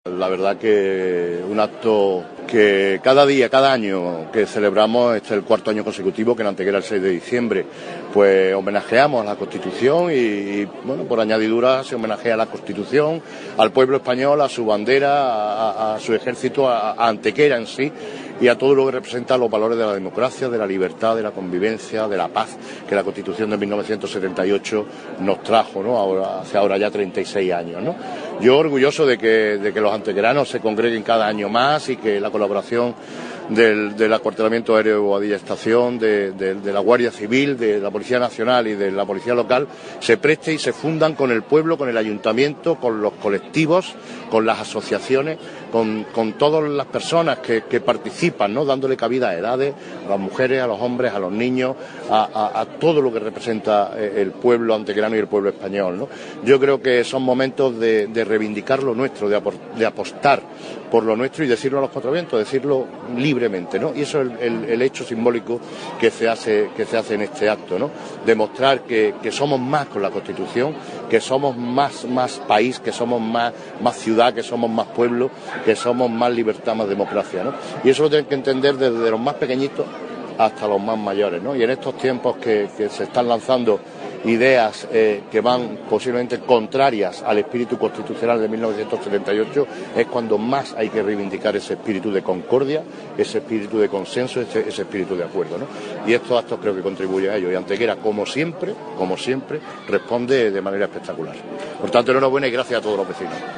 Generar Pdf martes 9 de diciembre de 2014 La ciudad de Antequera conmemoró el Día de la Constitución con un solemne acto institucional de homenaje presidido por el Alcalde Generar Pdf El alcalde de Antequera, Manolo Barón, presidió este sábado 6 de diciembre el acto institucional conmemorativo en nuestra ciudad del Día de la Constitución Española de 1978.
Cortes de voz M. Barón 710.01 kb Formato: mp3